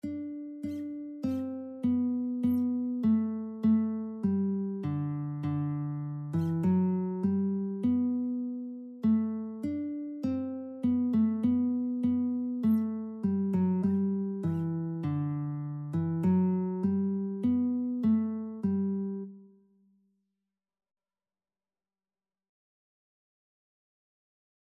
Christian Christian Lead Sheets Sheet Music The King of Love My Shepherd Is
G major (Sounding Pitch) (View more G major Music for Lead Sheets )
4/4 (View more 4/4 Music)
Traditional (View more Traditional Lead Sheets Music)